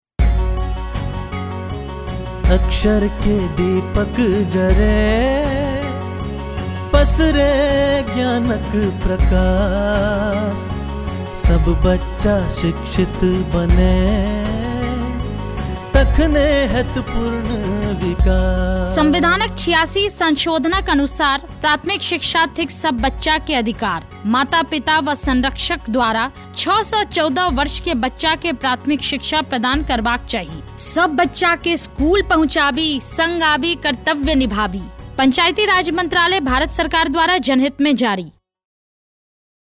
123 Fundamental Duty 11th Fundamental Duty Duty for all parents and guardians to send their children in the age group of 6-14 years to school Radio Jingle Maithili